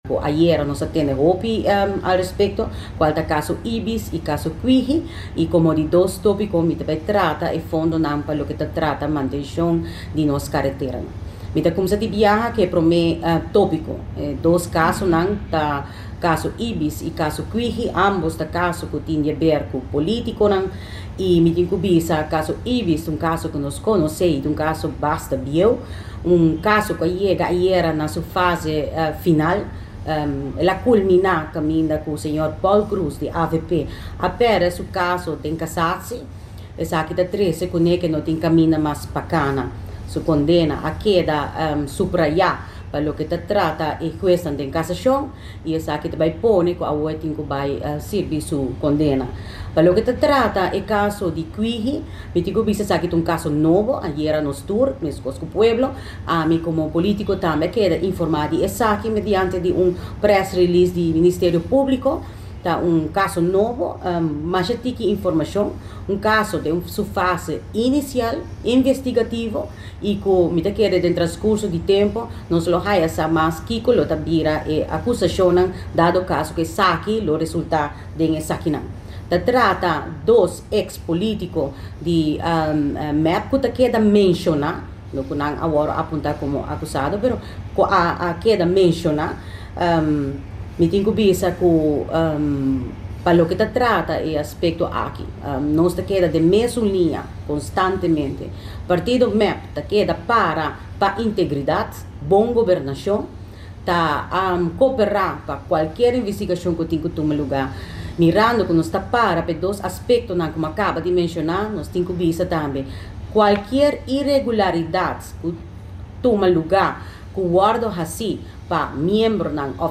Lider di fraccion di MEP den conferencia di prensa a trece padilanti dos topico importante, e prome topico ta trata di e caso di IBIS y tambe casi Kwihi, ambos caso tin di haber cu politiconan.